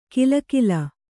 ♪ kilakila